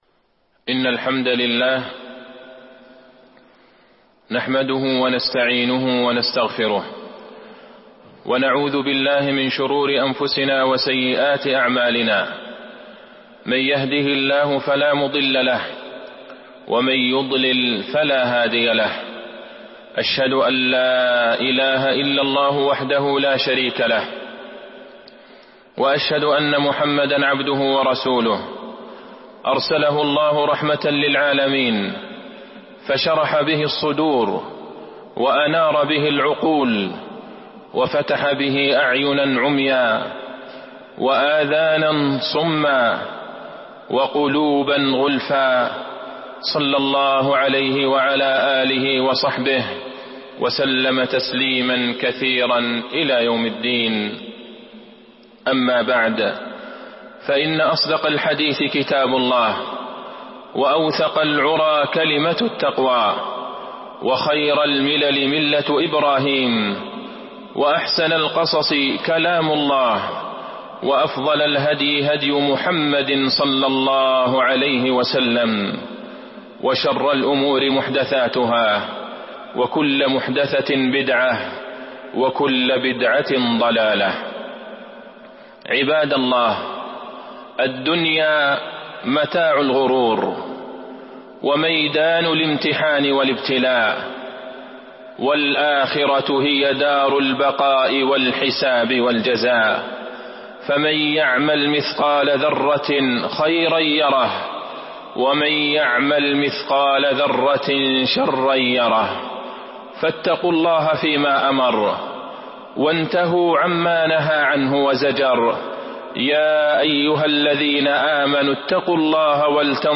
تاريخ النشر ٧ رجب ١٤٤٢ هـ المكان: المسجد النبوي الشيخ: فضيلة الشيخ د. عبدالله بن عبدالرحمن البعيجان فضيلة الشيخ د. عبدالله بن عبدالرحمن البعيجان تعظيم الأشهر الحرم